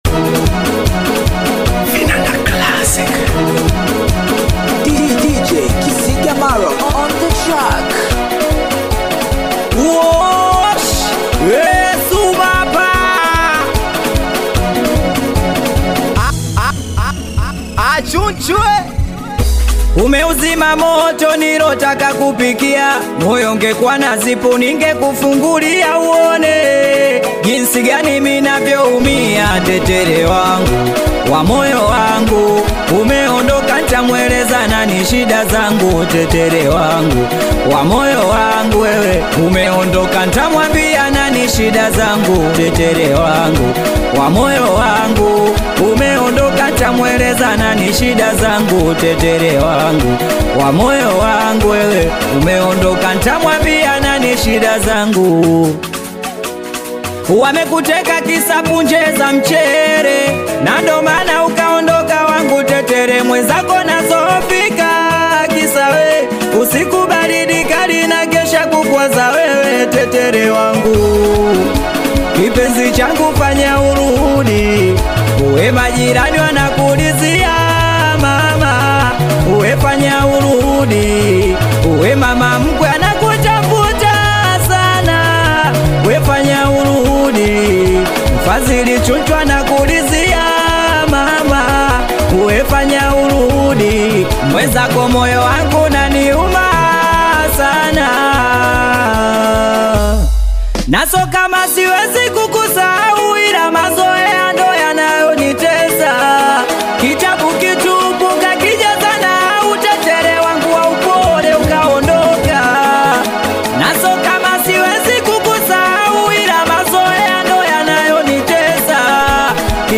Tanzanian Bongo Flava singeli
Singeli